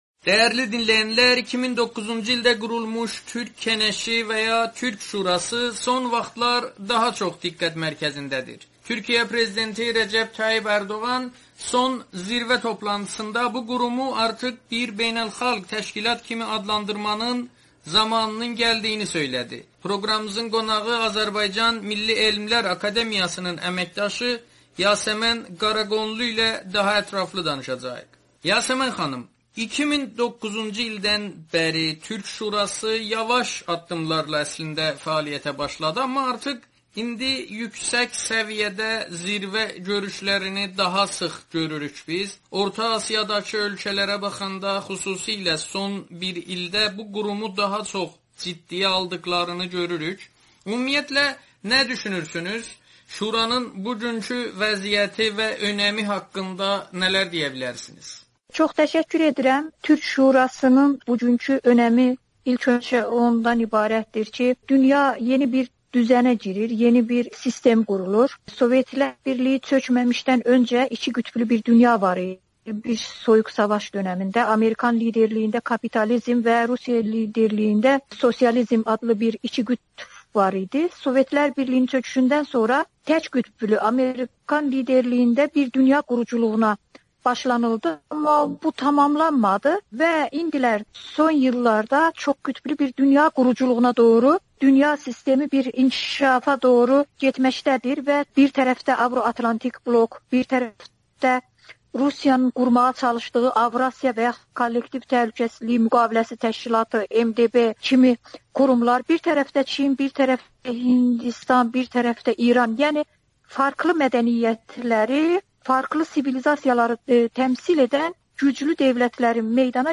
Amerikanın Səsi ilə söhbətdə